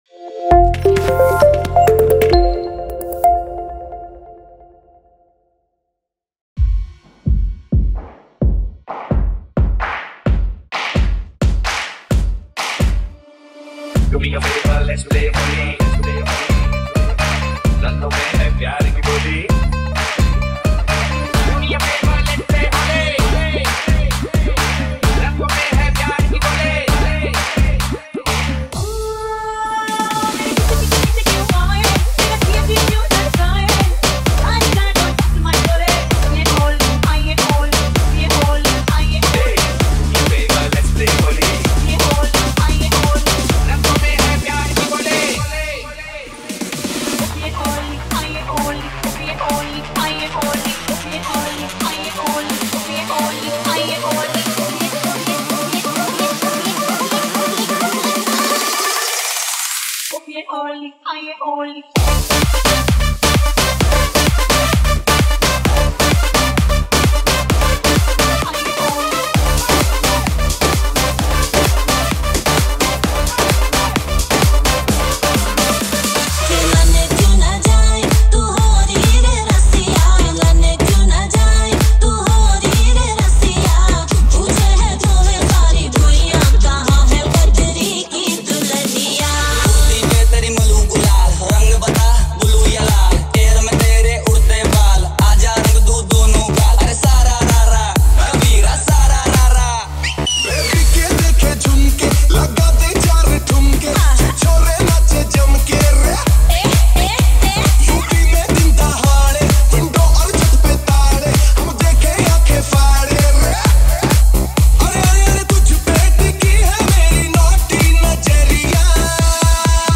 DJ Remix Songs